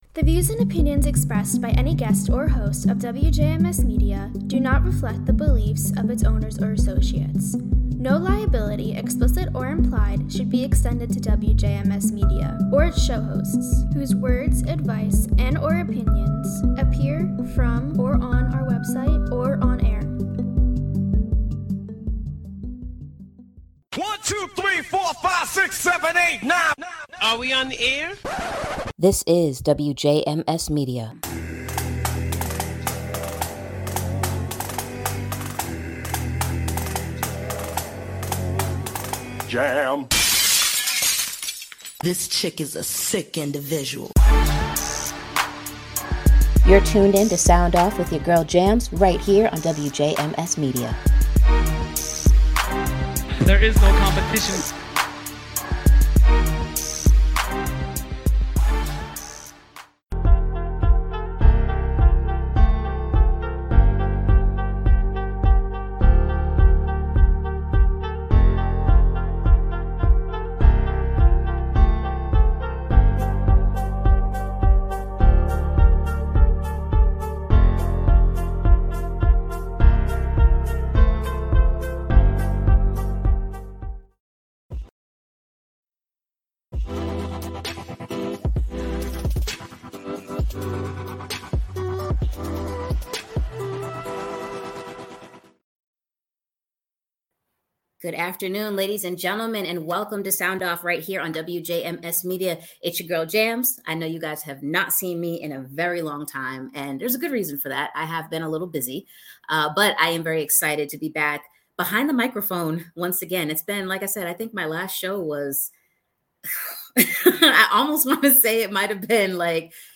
Tune in as they talk all things food! Better have a snack ready, this conversation is sure to make you hungry!